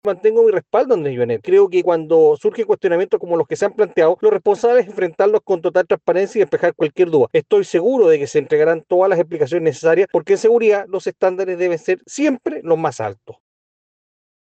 De hecho, desde el Partido Republicano, el diputado Agustín Romero hizo el llamado a enfrentar los cuestionamientos con transparencia.